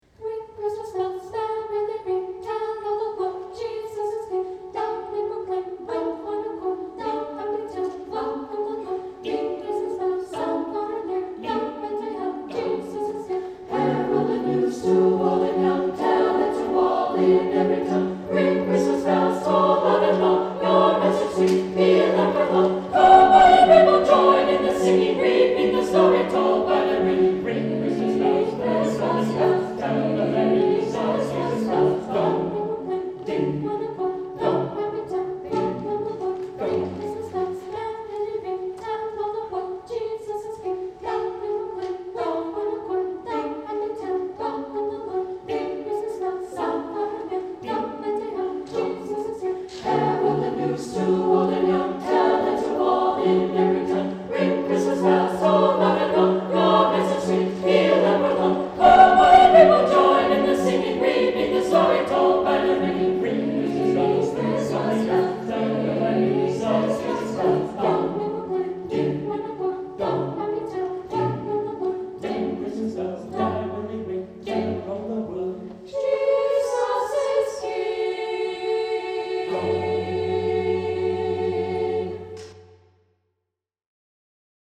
Saint Clement Choir Sang this Song
Anthem , Christmas-Vigil